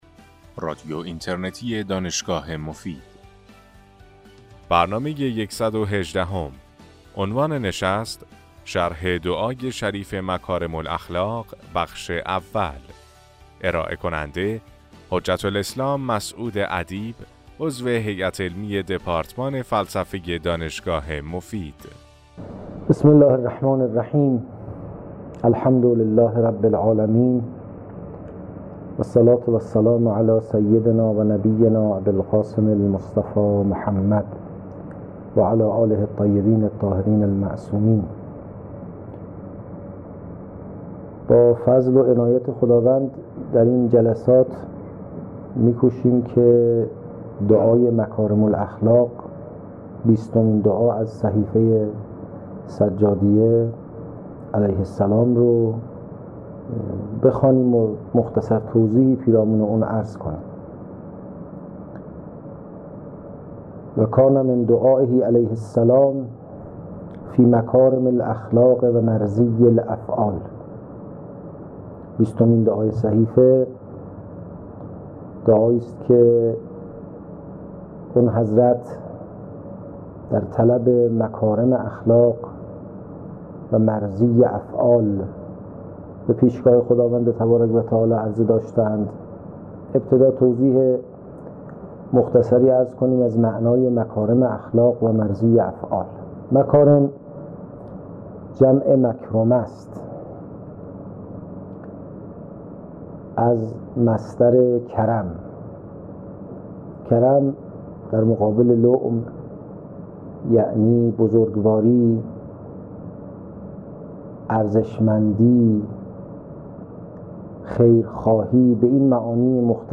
سحخنرانی